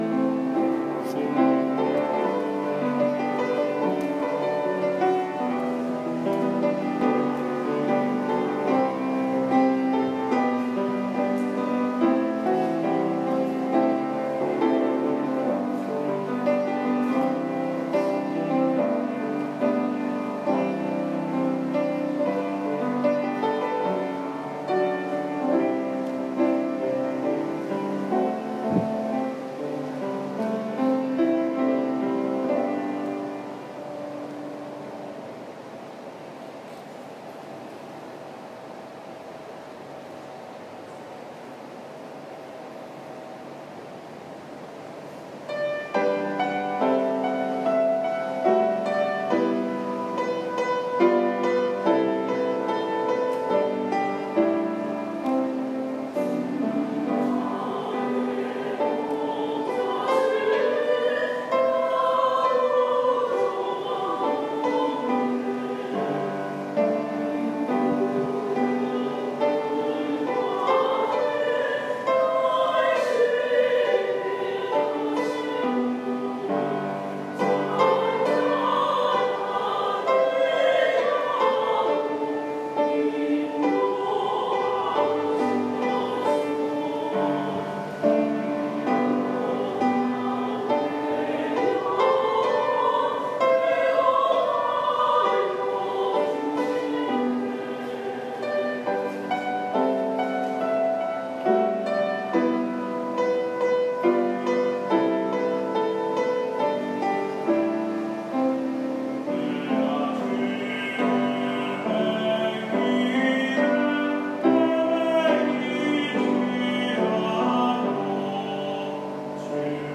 7월 10일 주일 찬양대 찬양(주는 나의 목자)